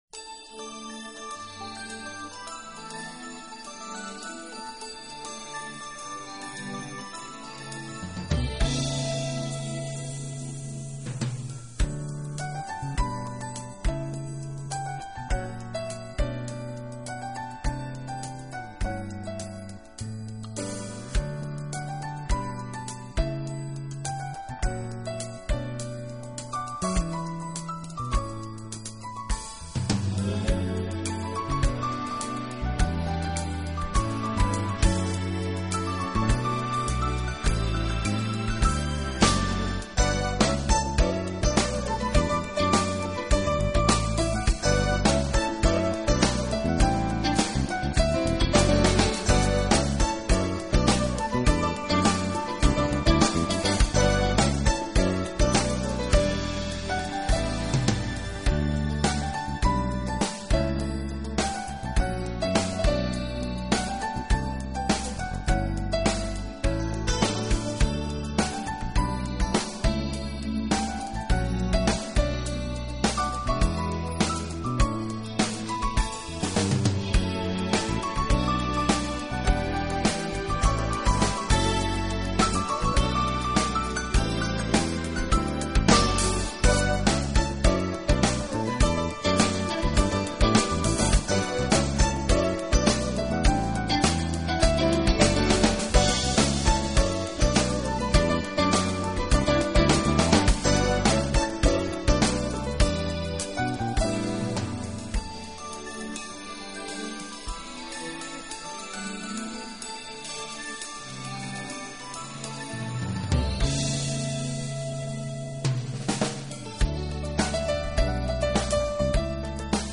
音乐类型：Jazz，Piano
的节奏和节拍变化，清新袭人。